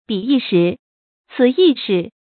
彼一时，此一时 bǐ yī shí，cǐ yī shí
彼一时，此一时发音
成语正音 一，不能读作“yì”。